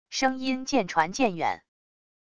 声音渐传渐远wav音频